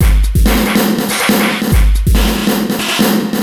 E Kit 05.wav